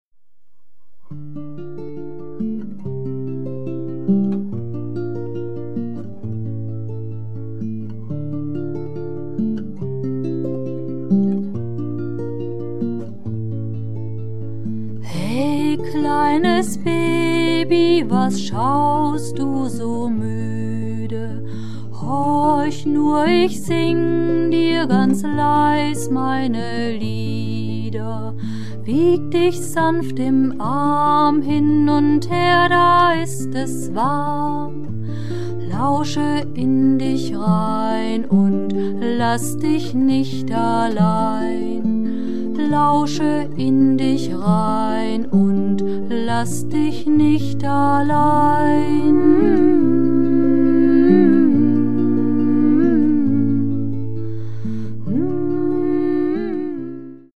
Tonaufnahmen (Multitrack): April-Juni 2006 in Deinstedt
Gesang und Gitarre